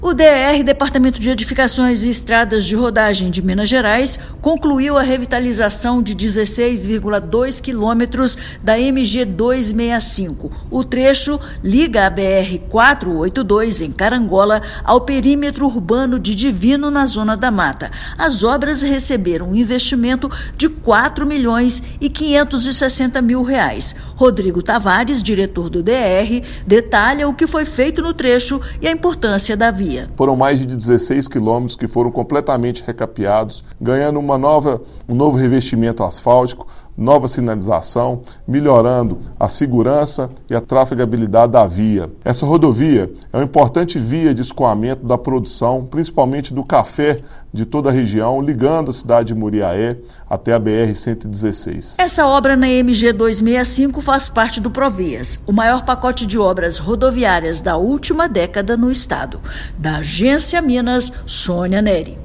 O Departamento de Edificações e Estradas de Rodagem de Minas Gerais (DER-MG) concluiu a revitalização de 16,2 quilômetros da MG-265, na Zona da Mata. Ouça matéria de rádio.